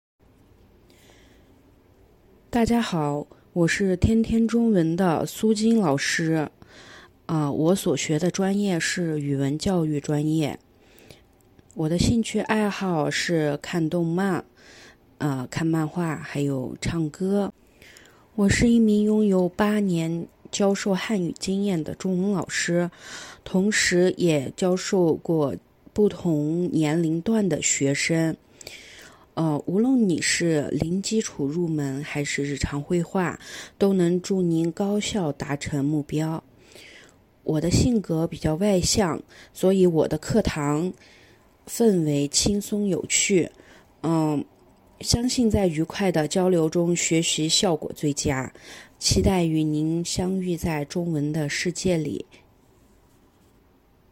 日本語NGですが、はきはきお話をされ、いつもニコニコして感じの良い先生です。